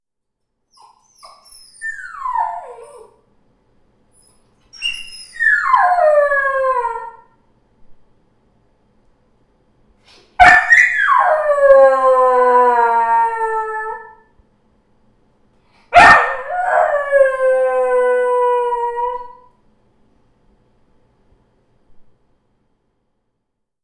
Proposição sonora: coleção (em processo) de uivos de cachorros (alternando-se 17 minutos de sons de uivos com 17 minutos de silêncio)
18. uivo solo quico
18.uivo_solo_quico.wav